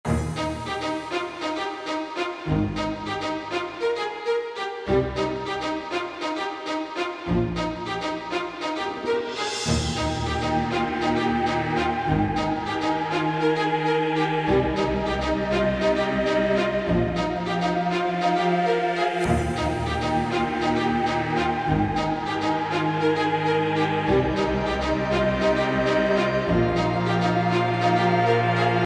Orchestra loop
The idea was that you could play the "intro" in the beggining and then after the intro finishes you'd just play the "loop" over and over again.